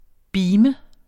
Udtale [ ˈbiːmə ]